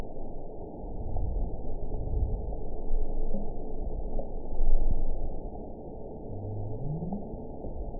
event 921692 date 12/16/24 time 22:39:22 GMT (11 months, 2 weeks ago) score 9.20 location TSS-AB03 detected by nrw target species NRW annotations +NRW Spectrogram: Frequency (kHz) vs. Time (s) audio not available .wav